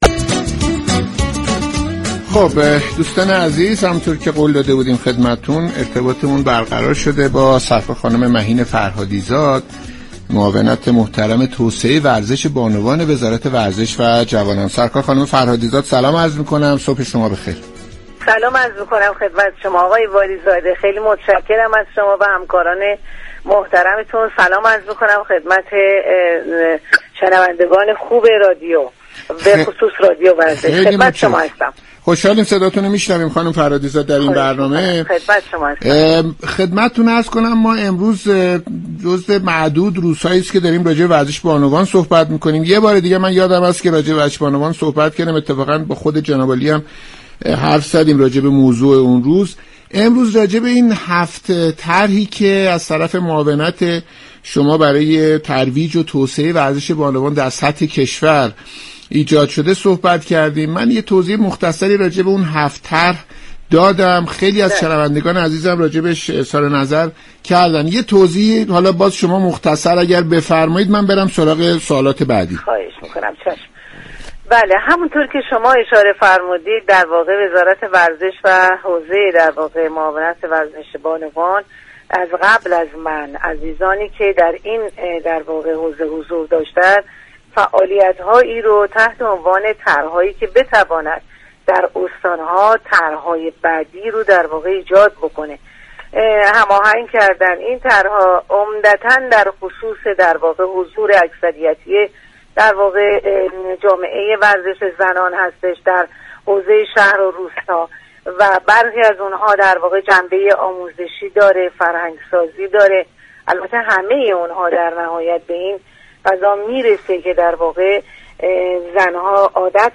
برنامه «صبح و ورزش» دوشنبه 2 تیر در گفتگو با مهین فرهادی زاد، معاون توسعه ورزش بانوان وزارت ورزش و جوانان به توضیح در خصوص 7 طرح این معاونت در جهت ترویج و توسعه ورزش در میان بانوان كشورمان پرداخت.